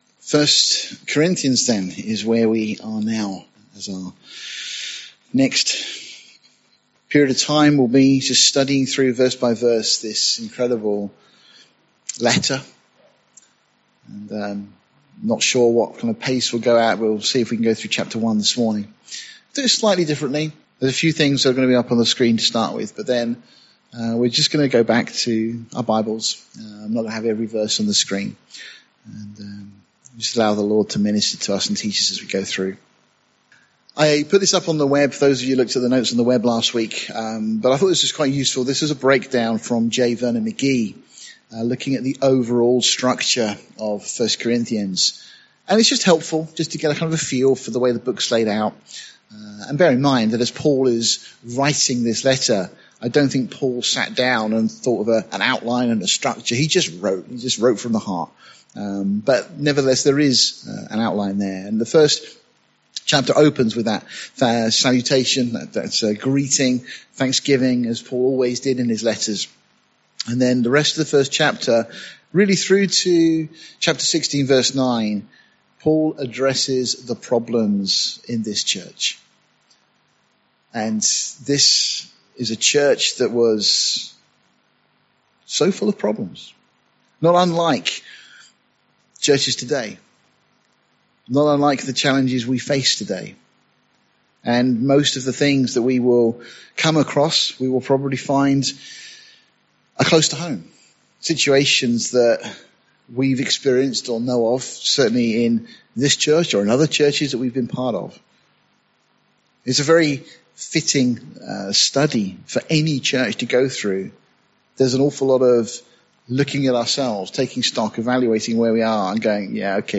Series: Sunday morning studies Tagged with verse by verse